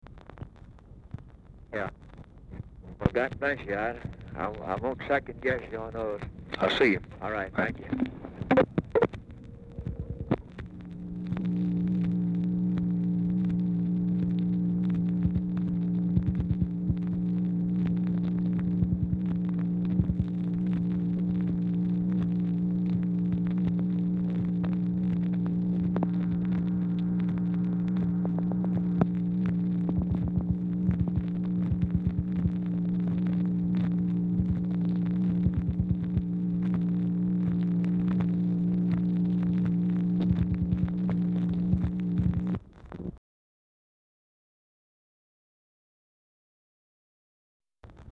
Telephone conversation # 3604, sound recording, LBJ and GEORGE SMATHERS, 6/1/1964, 2:48PM
Oval Office or unknown location
CONTINUES FROM 2 PREVIOUS RECORDINGS, BUT ONLY REPEATS FINAL PART OF PREVIOUS RECORDING; THIS RECORDING NOT TRANSCRIBED; 0:35 SECONDS OF MACHINE NOISE AT END OF RECORDING
Telephone conversation
Dictation belt